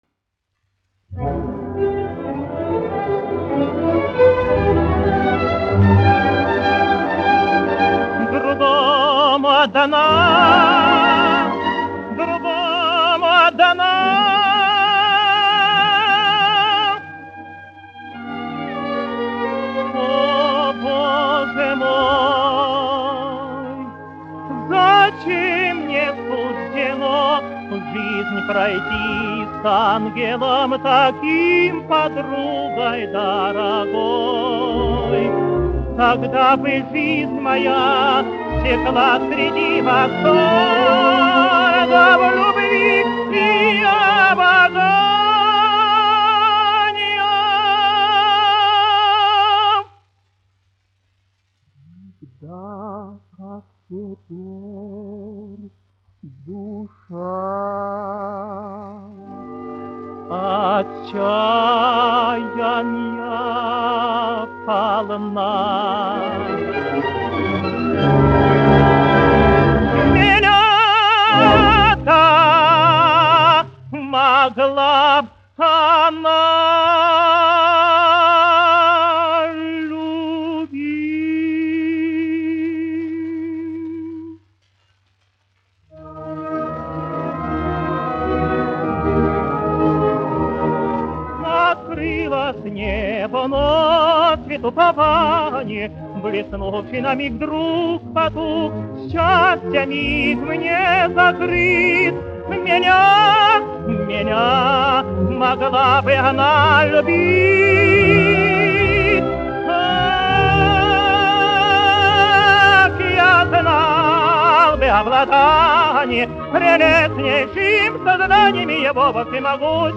Козловский, Иван Семенович, 1900-1993, dziedātājs
Московская государственная филармония. Симфонический оркестр, izpildītājs
Александр Иванович Орлов, diriģents
1 skpl. : analogs, 78 apgr/min, mono ; 25 cm
Operas--Fragmenti
Skaņuplate